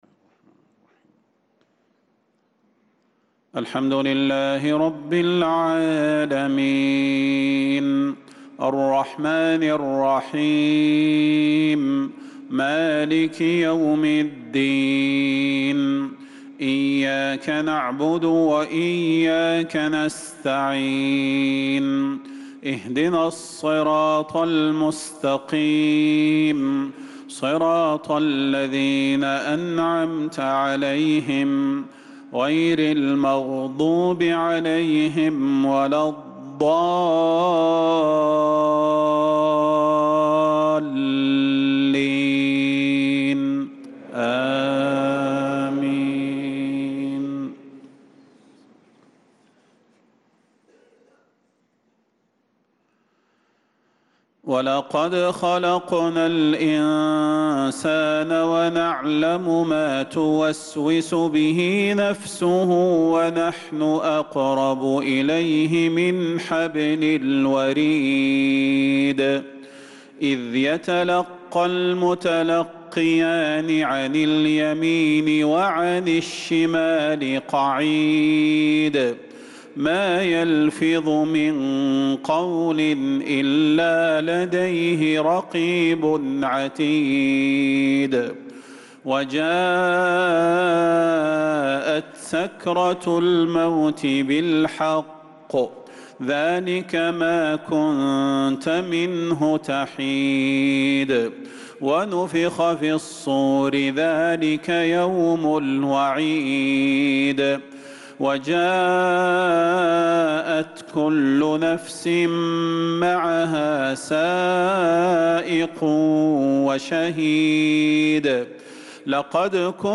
صلاة العشاء للقارئ صلاح البدير 17 شوال 1445 هـ
تِلَاوَات الْحَرَمَيْن .